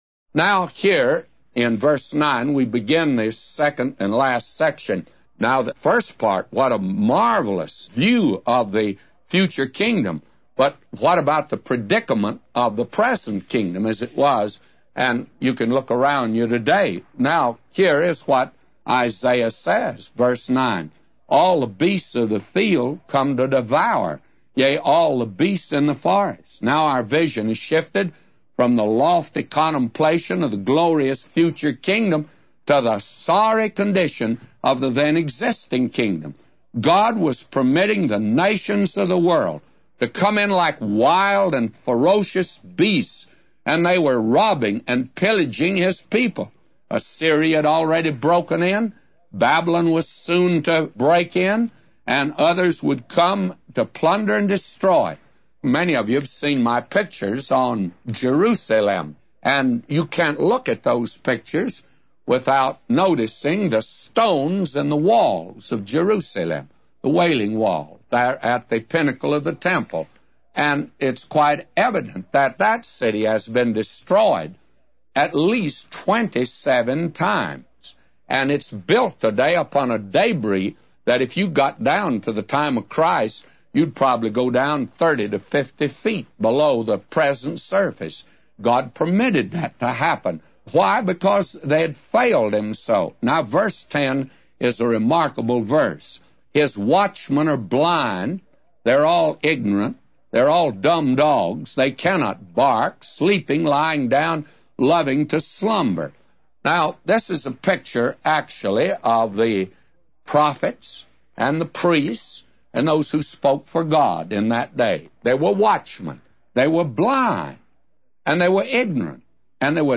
A Commentary By J Vernon MCgee For Isaiah 56:9-999